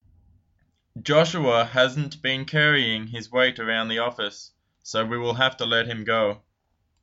英語ネイティブによる音声はこちらです。